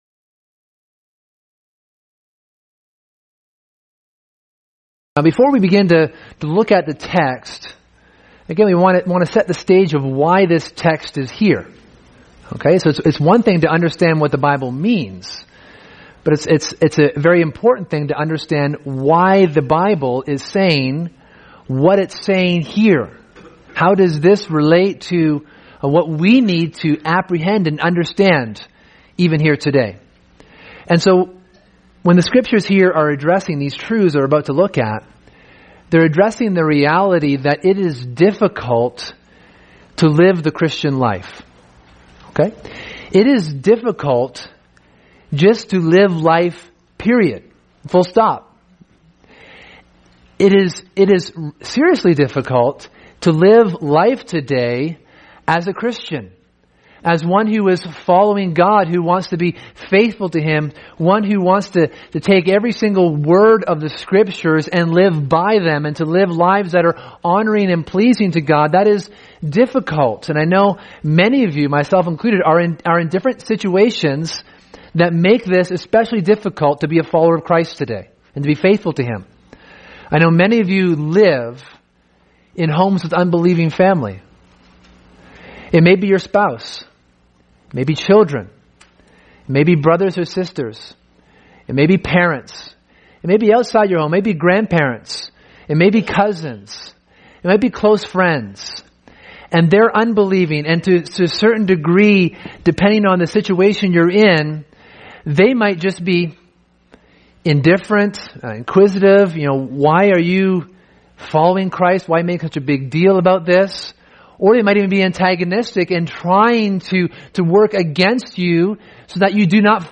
Sermon: Christ: Our Great High Priest